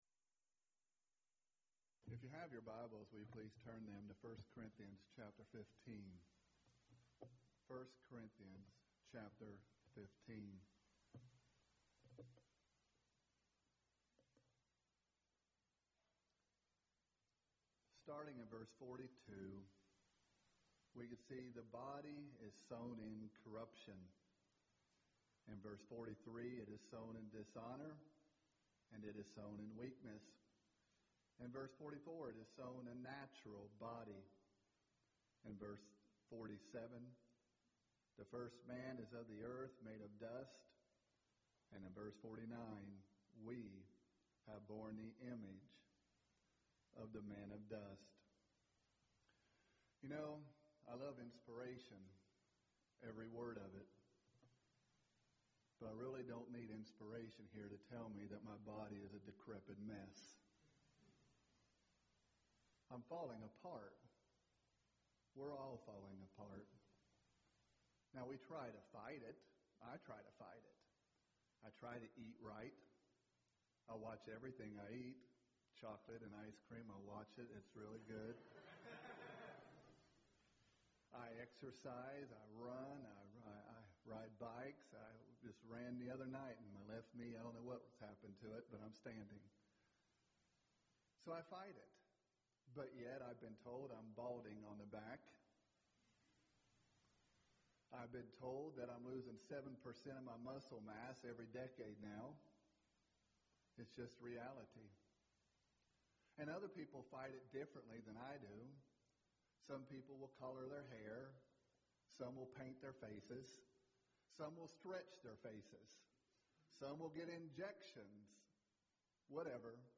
Event: 3rd Annual Colleyville Lectures
lecture